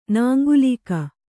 ♪ nāŋgulīka